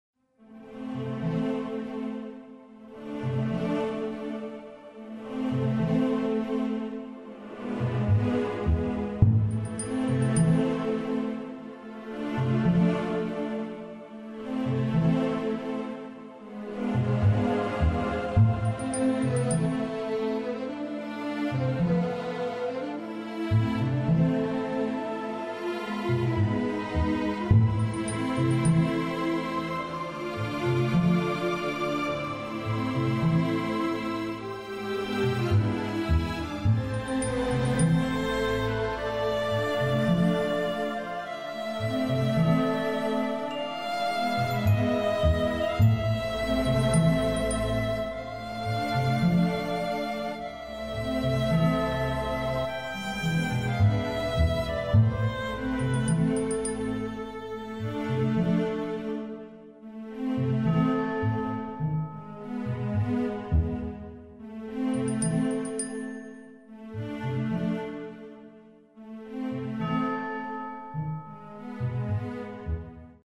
И вот как бы очередное творение...:hunter: Размер файла: 4.41 mb Качество: 128kbps MP3 Время: 4:06 Стиль: лаунджеватый:yes: Темп: 75 bpm Линк: *** У вас...